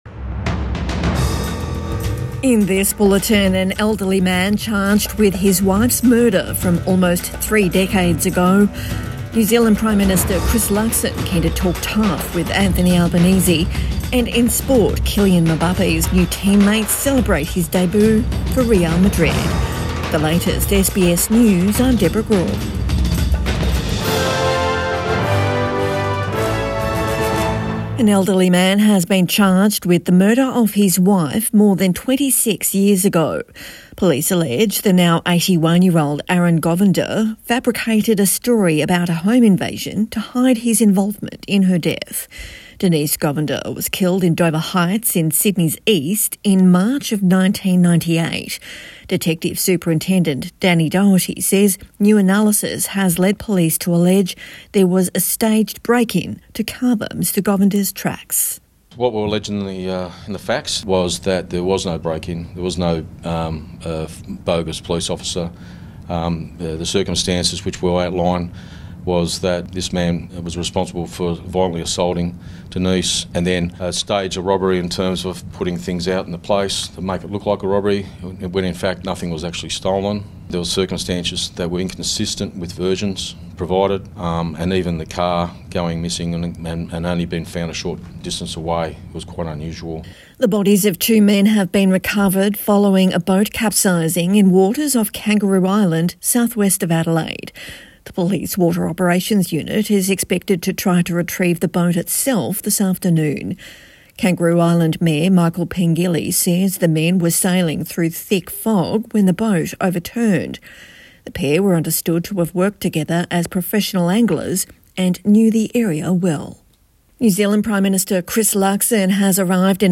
Evening News Bulletin 15 August 2024